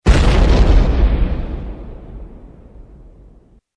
missile_sink.mp3